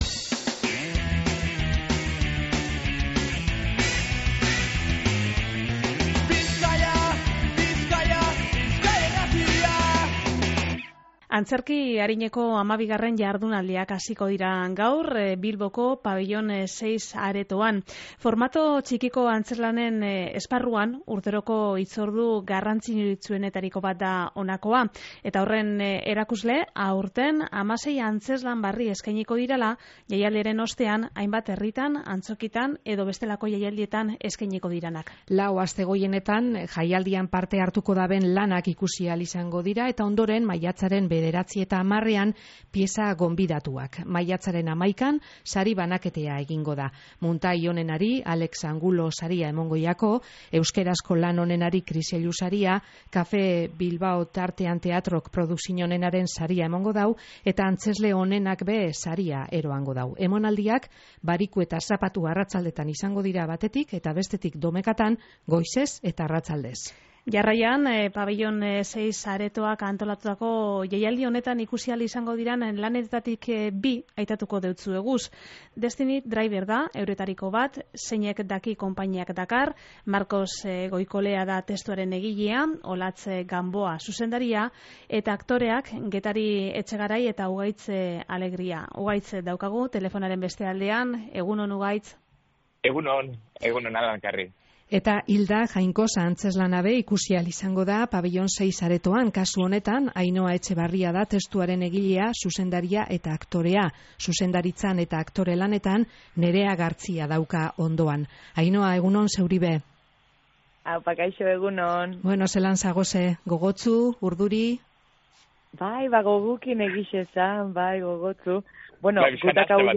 Jaialdi honetan lehian izango diren antzezlan biren protagonistekaz egin dogu berba gaur Lau Haizetara irratsaioan.